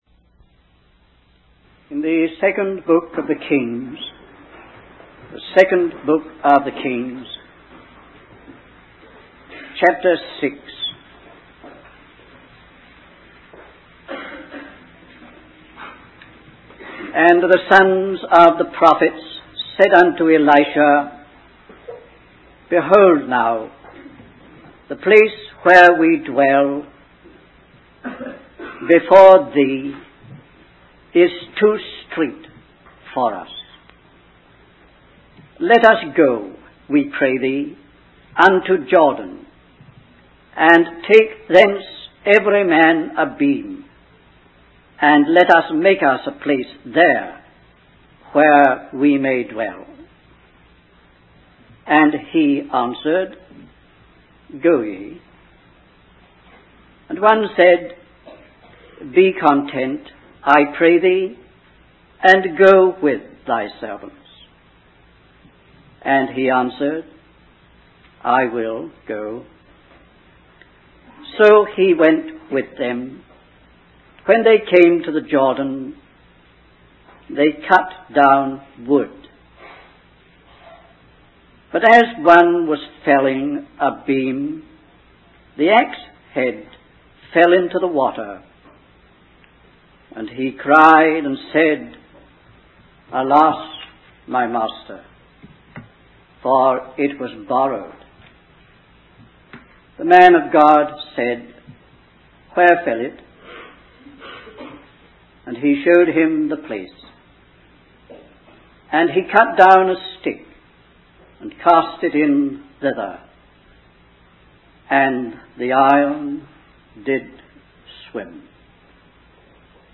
In this sermon, the speaker emphasizes the importance of staying close to life and keeping our ambitions and quests for enlargement and increase in line with the work of the Lord. The story of Elisha and the floating axe head is used as an example of a miraculous reversal of the natural order.